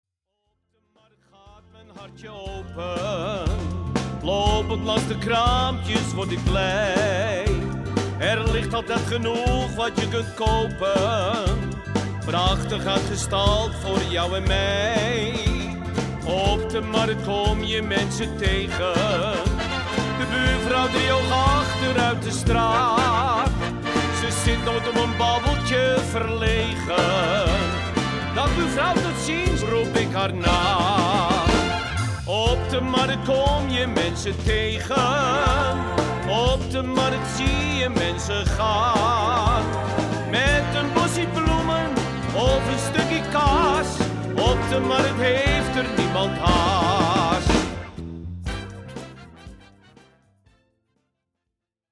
Uiteraard is dit geen CD-kwaliteit.